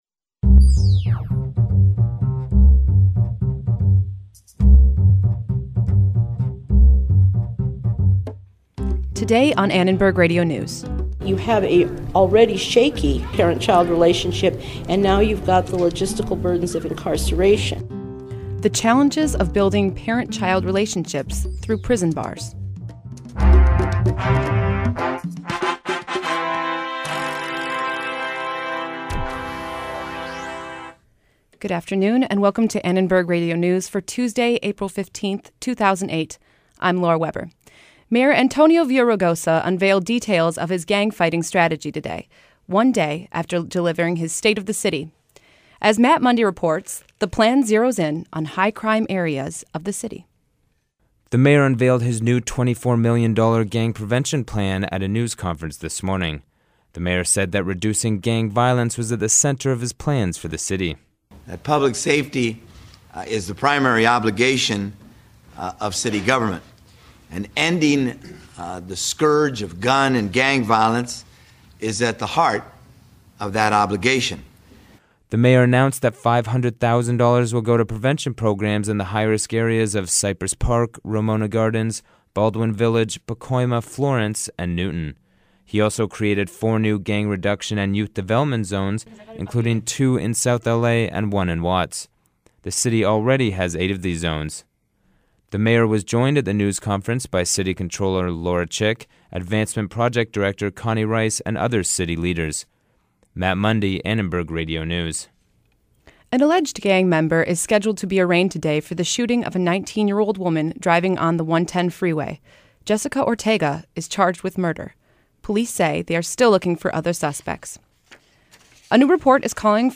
We look at the mayor's has a news proposal to crack down on gangs. One South L.A. group tells us what they're doing to help keep kids off the streets. Plus hear relatives of incarcerated parents speak about the challenges of parenting behind bars.